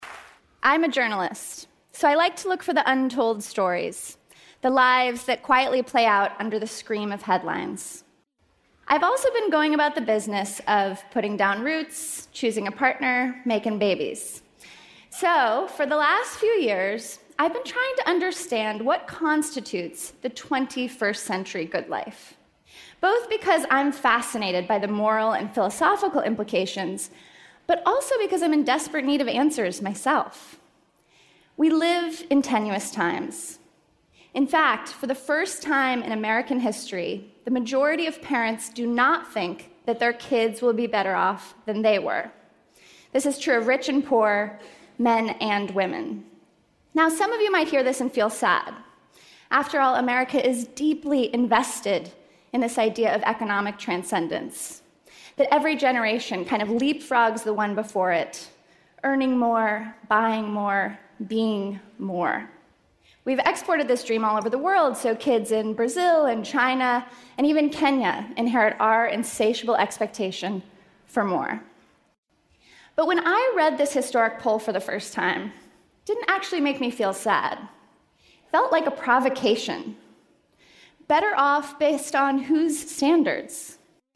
TED演讲:新美国梦(1) 听力文件下载—在线英语听力室